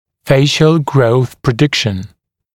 [‘feɪʃl grəuθ prɪ’dɪkʃn][‘фэйшл гроус при’дикшн]прогноз лицевого роста, прогноз роста лицевого отдела черепа